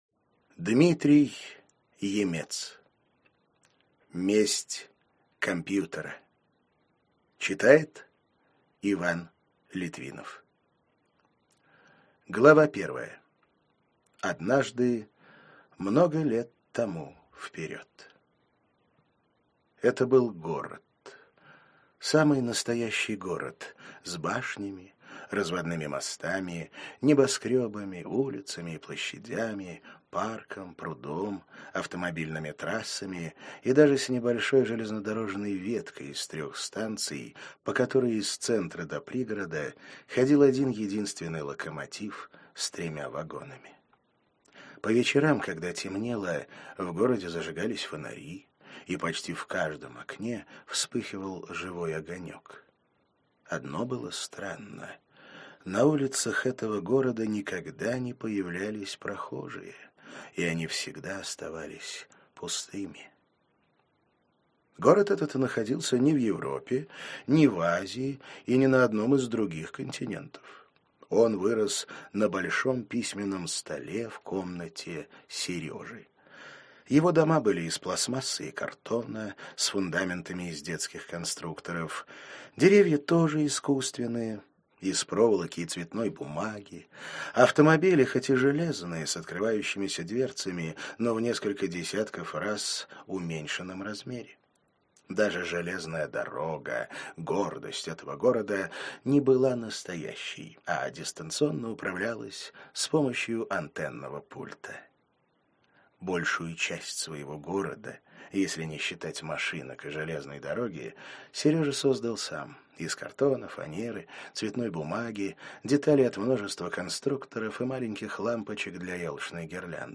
Аудиокнига Месть компьютера | Библиотека аудиокниг